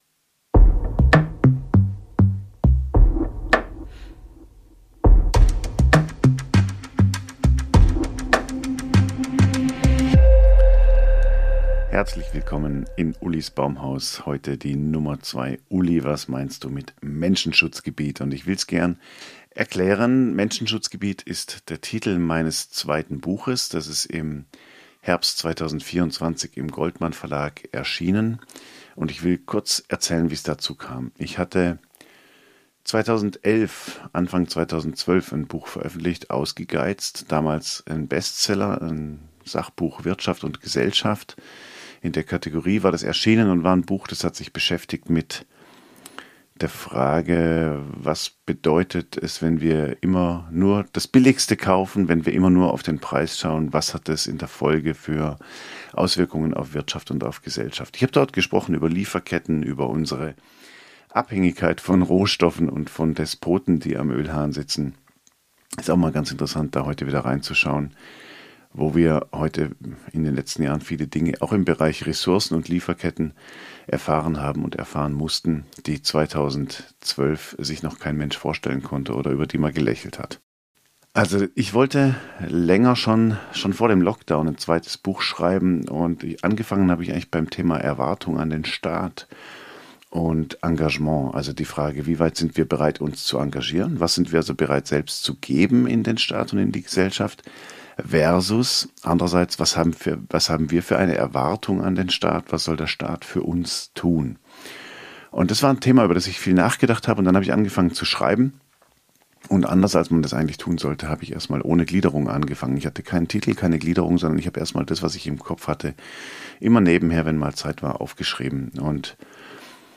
Im Auftakt von Ulis Baumhaus geht es um das Dorf – Symbol für Nähe, Vertrautheit und Überschaubarkeit. Was wir aus dem Dorf der Vergangenheit für die Stadt von morgen lernen können, erzähle ich mit persönlichen Gedanken und Lesestücken aus meinem Buch "Menschenschutzgebiet" (Goldmann, 2024).